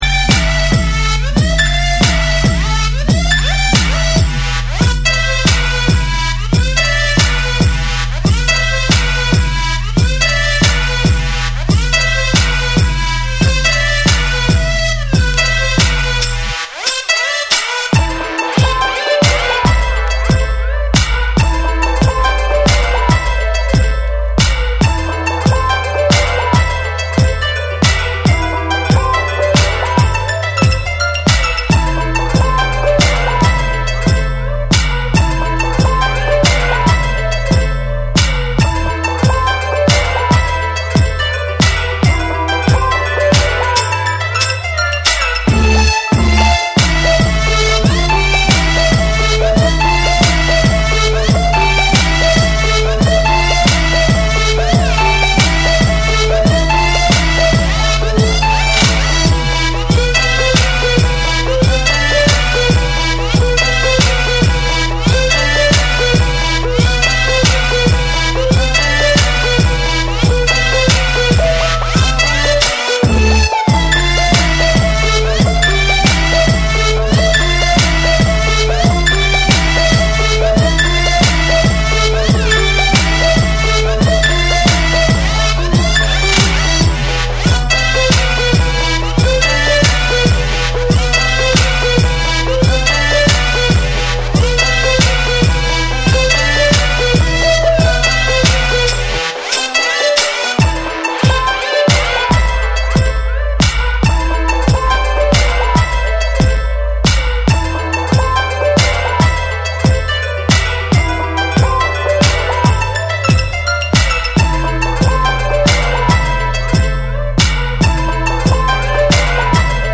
軽やかに跳ねるリズムとメロディックなフレーズが印象的な、UKガラージ〜ダブステップを横断するフロアチューン
BREAKBEATS / DUBSTEP